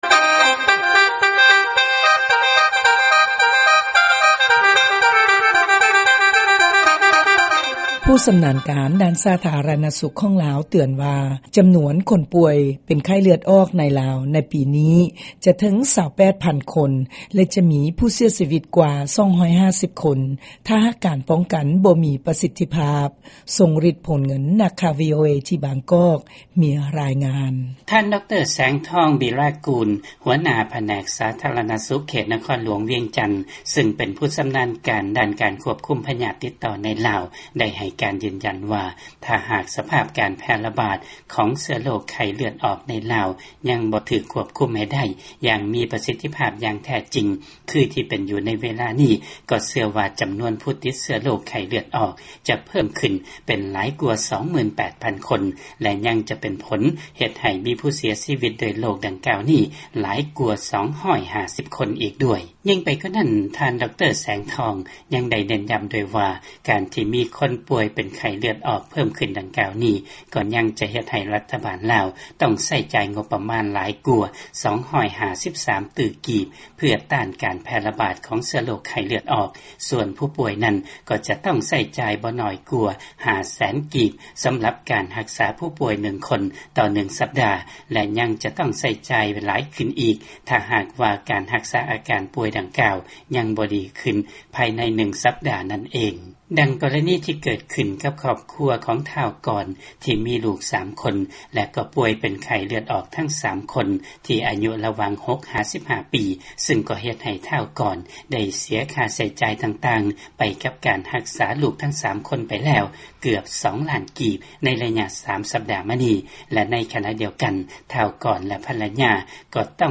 ຟັງລາຍງານໄຂ້ເລືອດອອກທີ່ລາວ